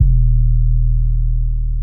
808s
REDD 808 (3).wav